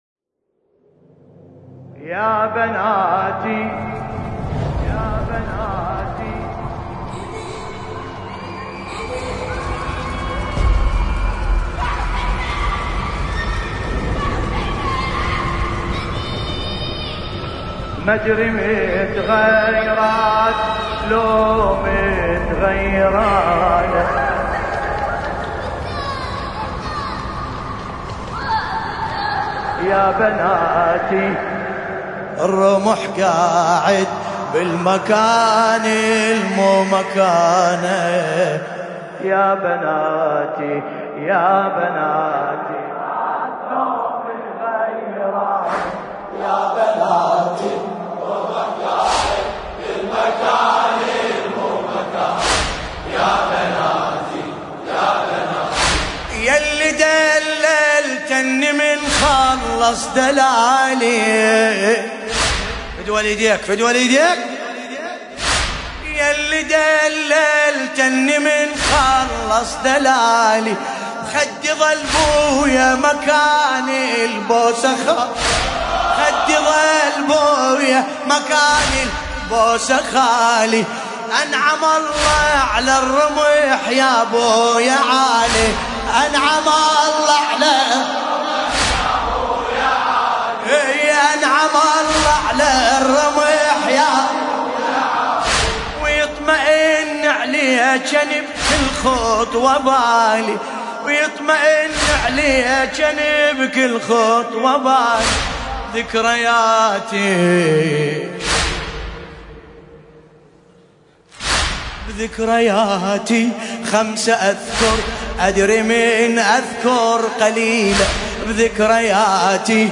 ملف صوتی يا بناتي بصوت باسم الكربلائي
قصيدة
المناسبة : ليلة 18 محرم 1440 حسينية الحاج داوود العاشور